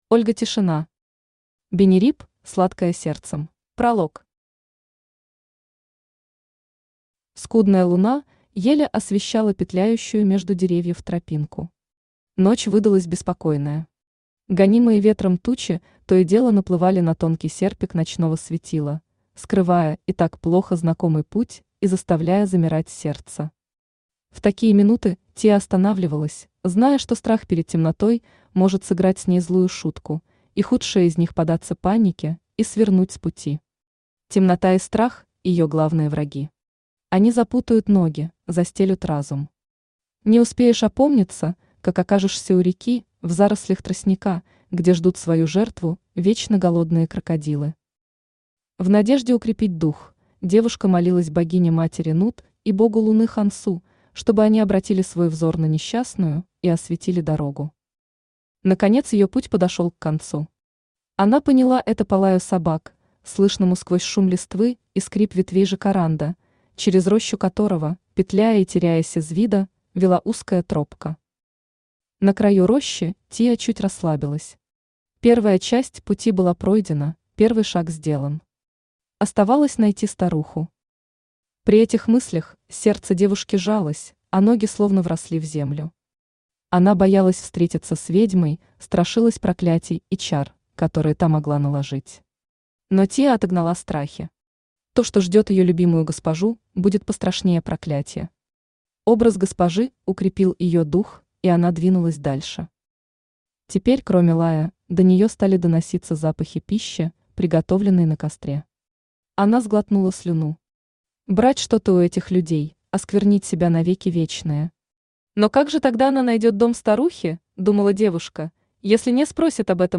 Aудиокнига Бенериб – сладкая сердцем Автор Ольга Тишина Читает аудиокнигу Авточтец ЛитРес.